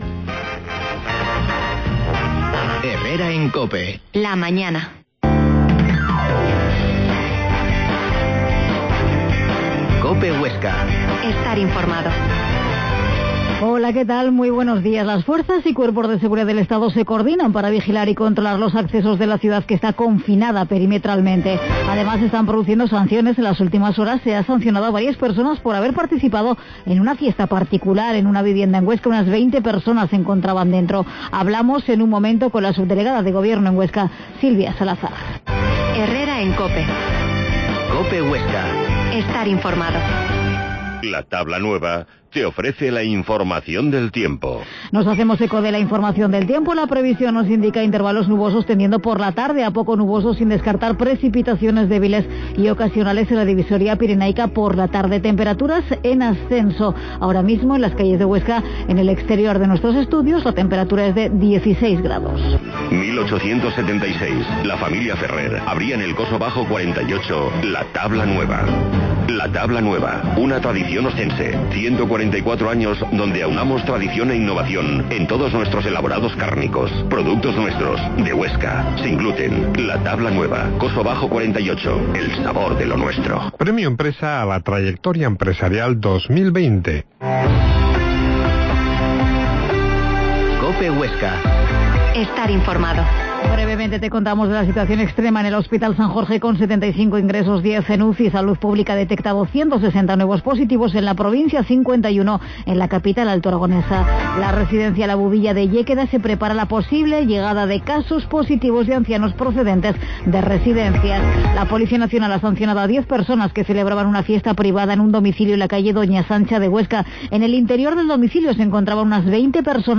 Herrera en COPE Huesca 12.50h Entrevista a la subdelegada de gobierno en Huesca, Silvia Salazar